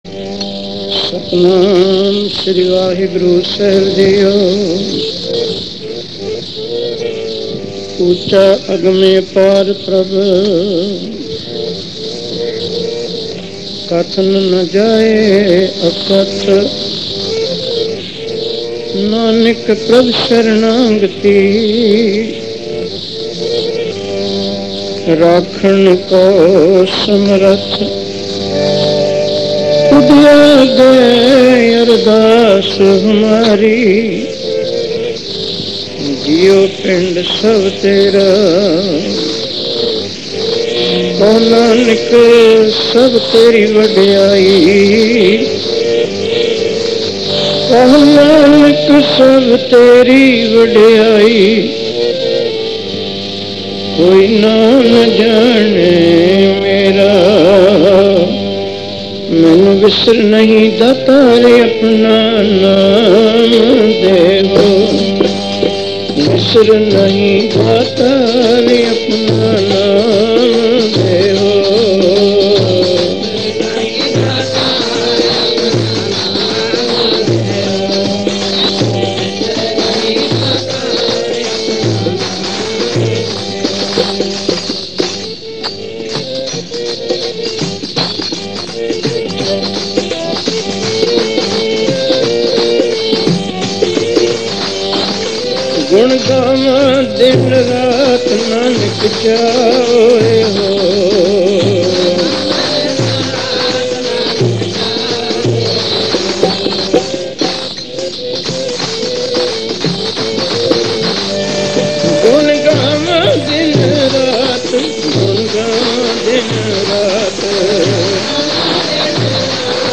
Album:Sakhi Vali Kandhari Genre: Gurmat Vichar